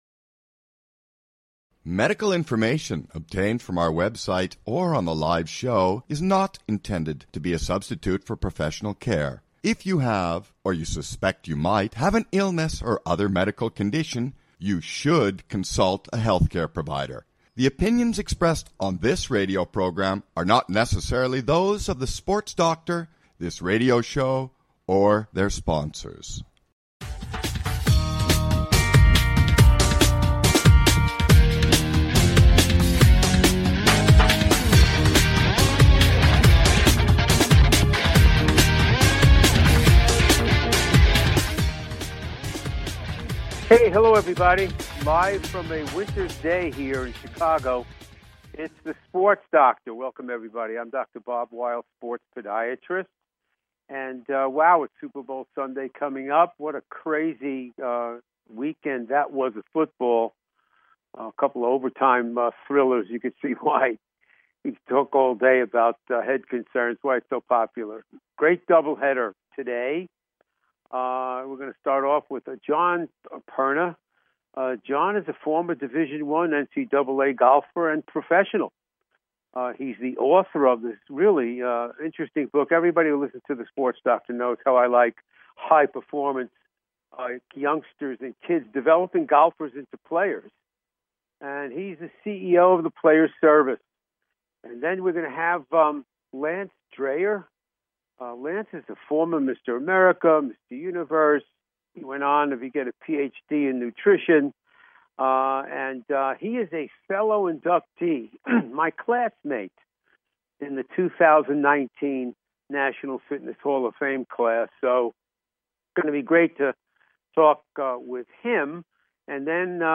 Talk Show Episode
Then, it's 'The Sports Doctor's In' with your questions and emails!